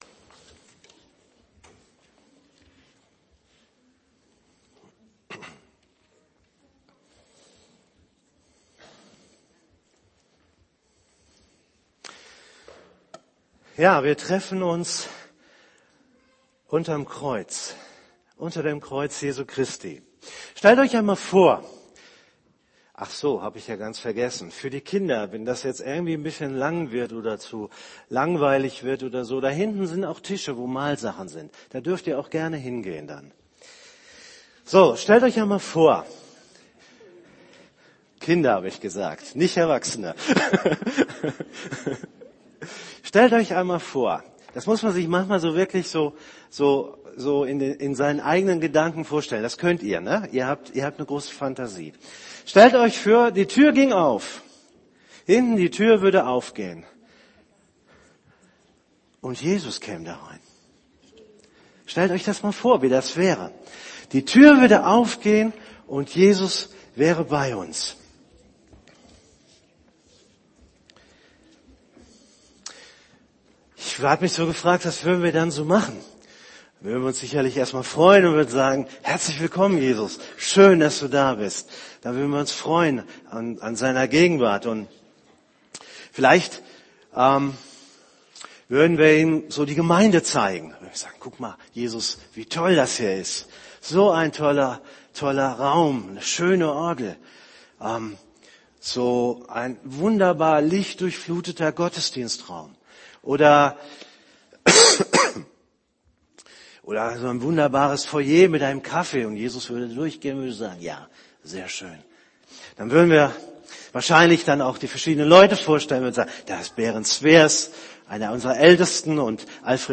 > Übersicht Predigten Gemeinde nach Jesus Sinn - Platz f�r alle: Kinder, Blinde, Gro�e, Kleine, ... so wie ich bin (Familiengottesdienst) Predigt vom 05.Mai 2013 Predigt Predigttext: Mk 10,13-16 Mk 10,46-52